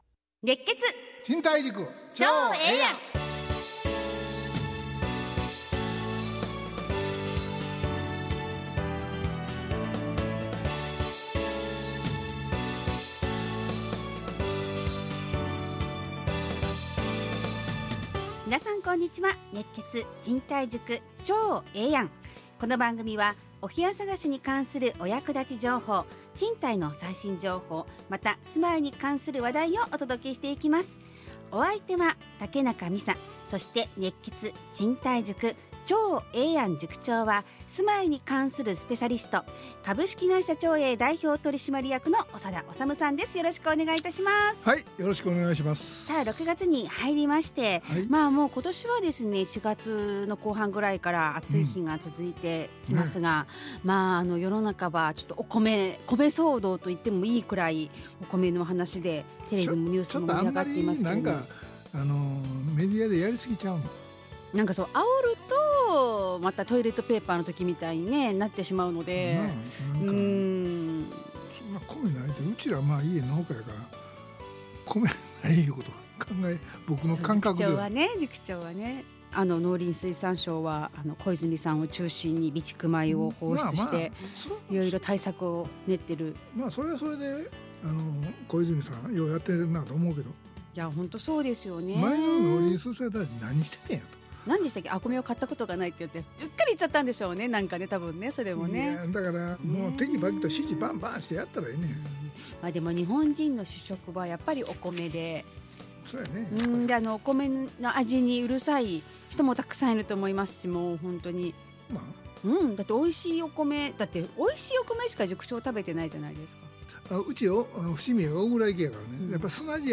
ラジオ放送 2025-06-06 熱血！賃貸塾ちょうええやん【2025.6.6放送】 オープニング：令和米騒動、通常になるのはいつ？